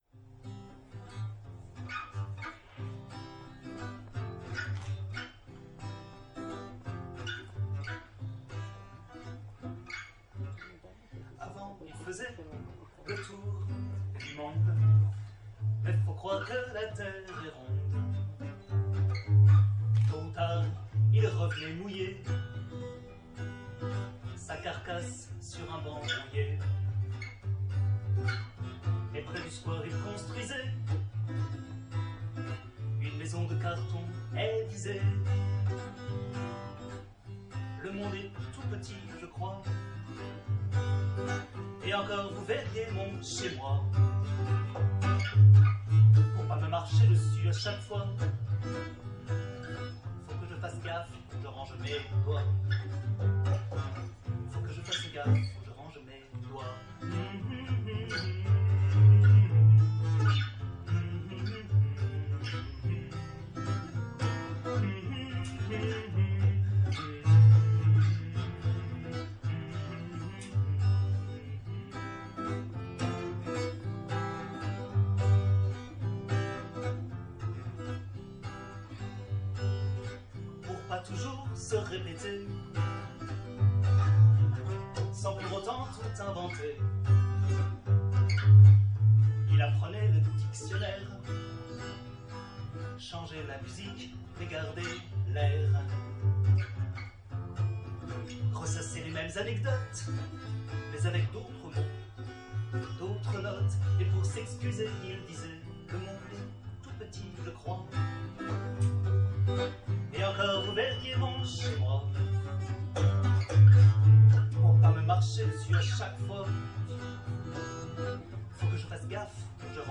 (Je sais, la basse saoule, mais bon.)